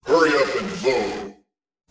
🌲 / midnight_guns mguns mgpak0.pk3dir sound announcer
vote_hurryup_00.wav